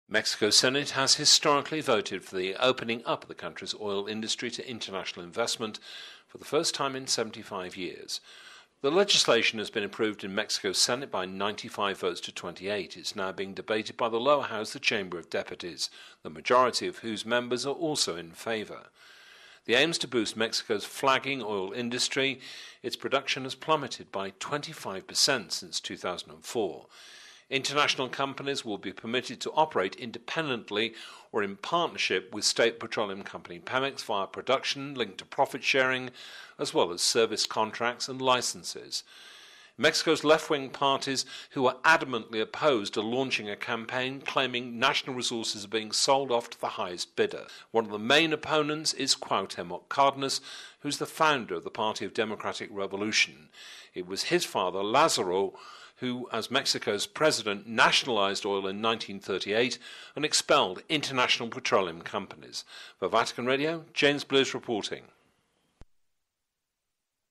(Vatican Radio) Mexico's Senate has historically voted for the opening up of the country's oil industry to international investment, for the first time in 75 years.